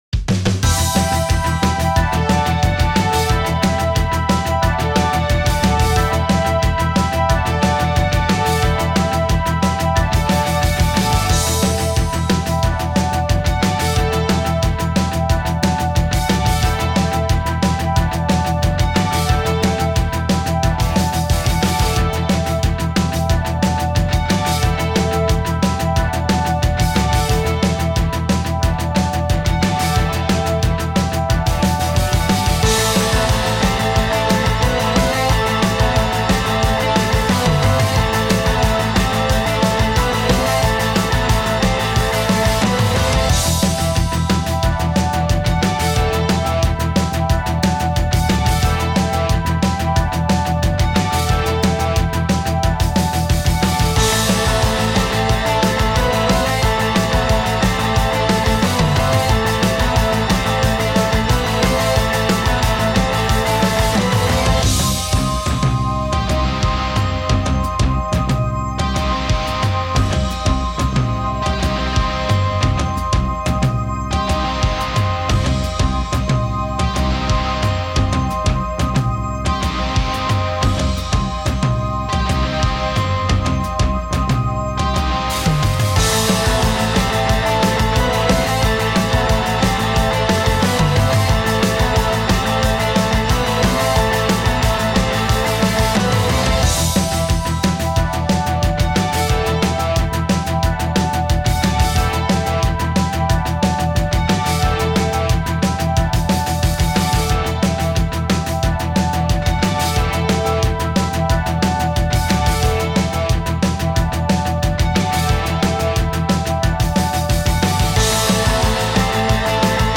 Übungsaufnahmen - Eiszeit
Eiszeit (Playback)
Eiszeit__6_Playback.mp3